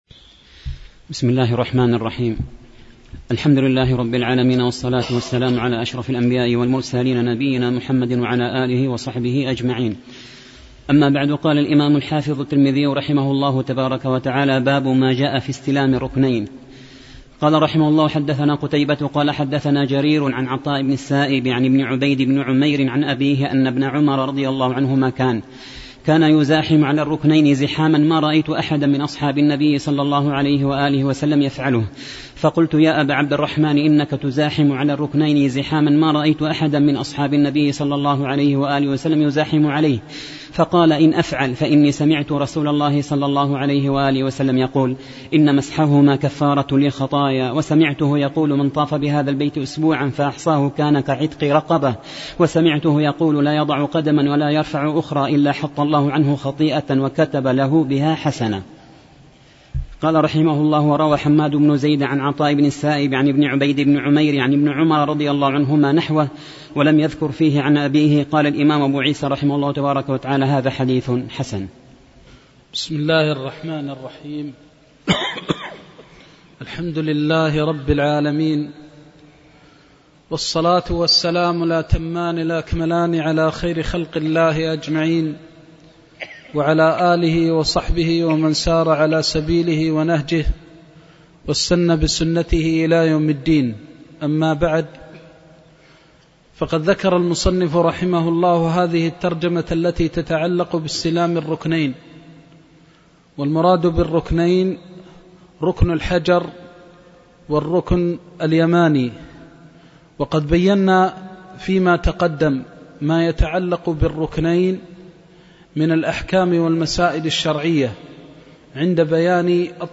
الدرس الثامن والستون والأخير باب ما جاء في استلام الركنين